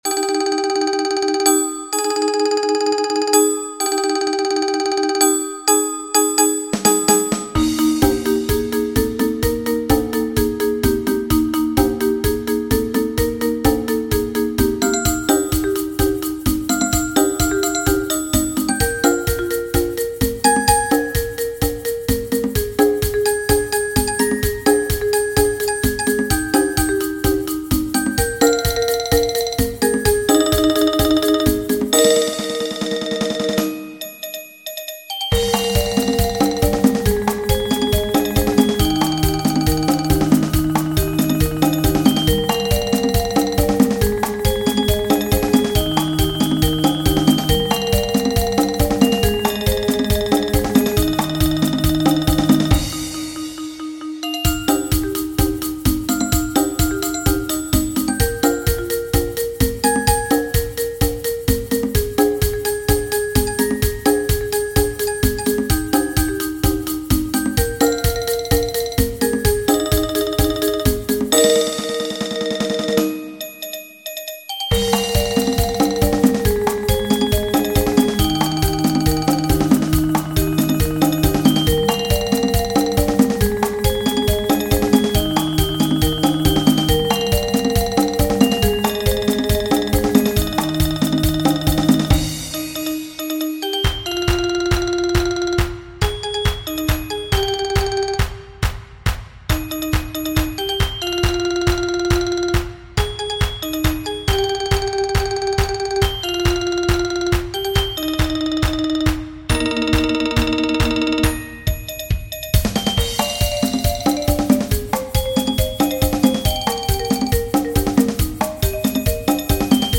Mallet-Steelband
Klokkenspel Xylofoon Marimba Drumstel Conga's shaker